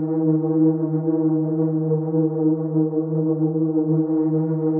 SS_CreepVoxLoopA-07.wav